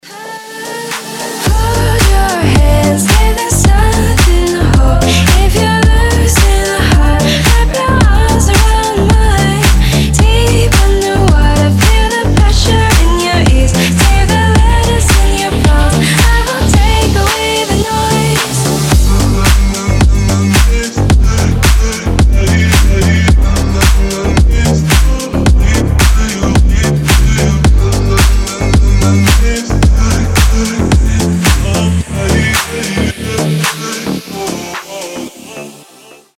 deep house
женский голос
Dance Pop
чувственные
Заводная танцевальная поп-песня на звонок телефона